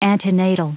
Prononciation du mot antenatal en anglais (fichier audio)
Prononciation du mot : antenatal